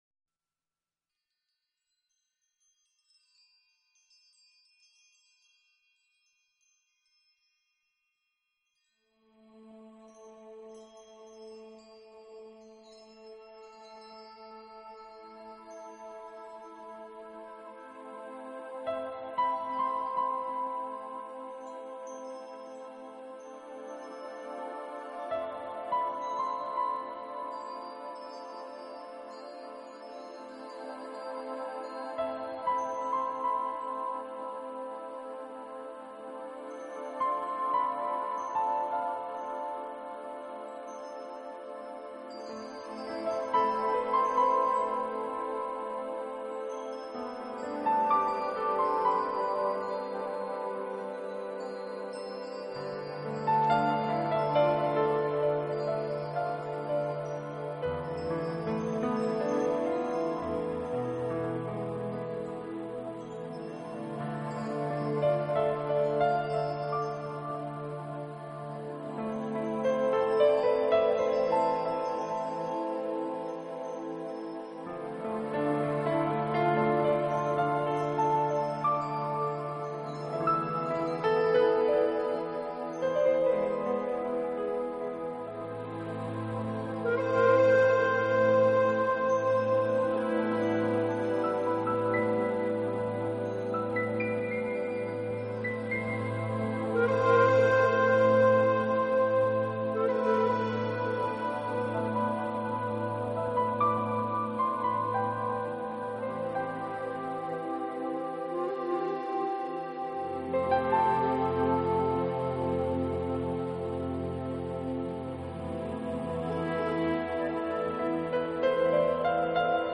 音乐类型：Piano solo